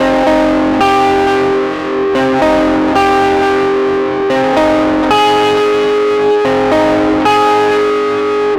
Track 15 - Guitar 04.wav